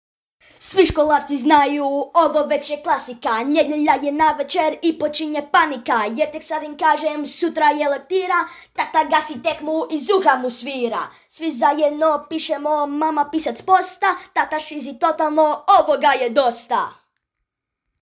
Serbian child voice over